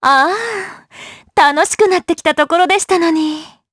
Laudia-Vox_Victory_jp.wav